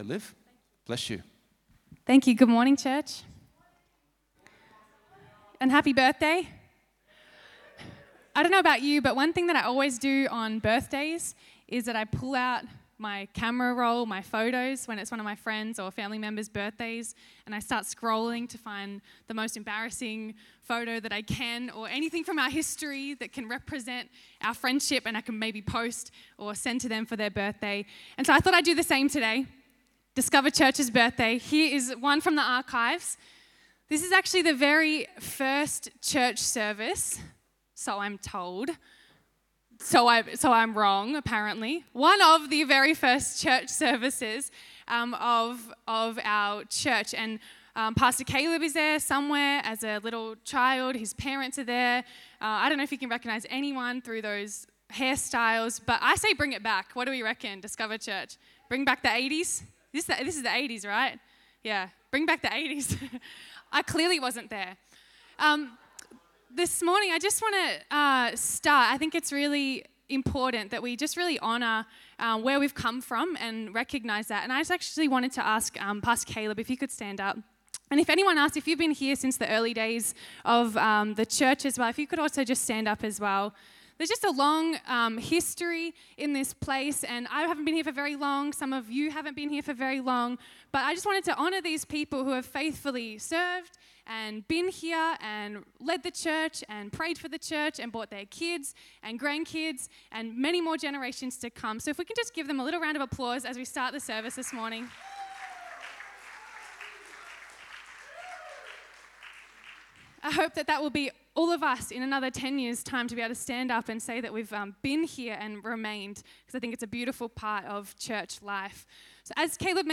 Sermons | Discover Church